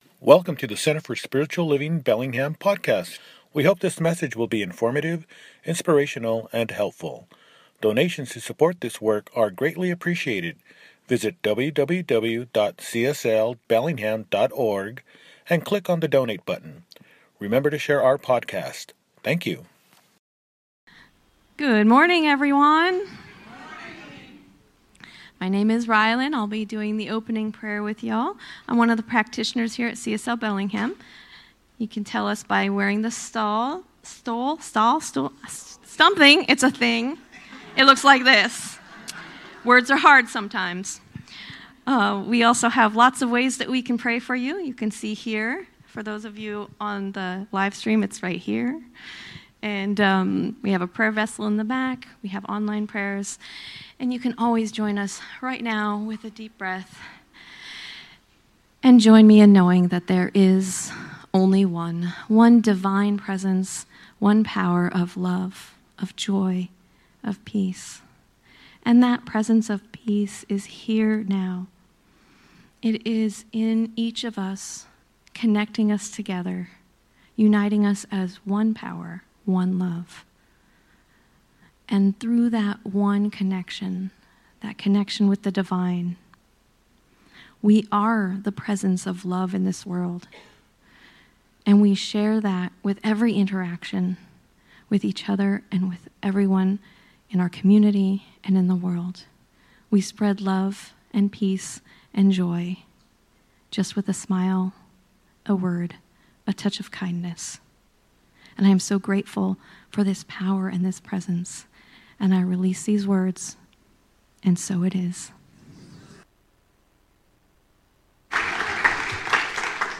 Soaring in Love – Celebration Service